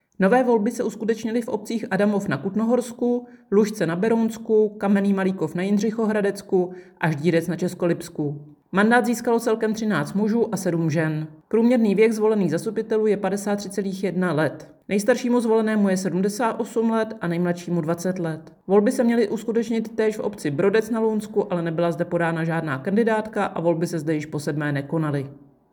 Vyjádření Evy Krumpové, 1. místopředsedkyně Českého statistického úřadu, soubor ve formátu MP3, 1007.81 kB